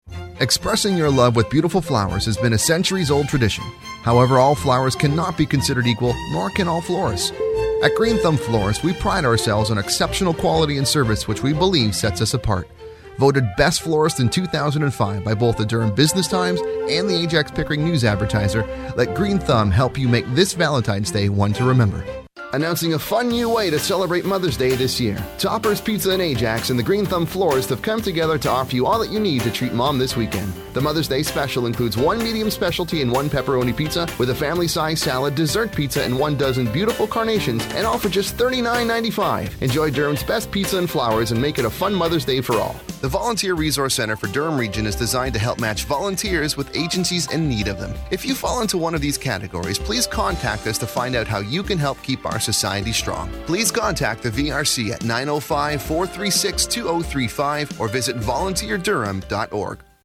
Voice Talent Services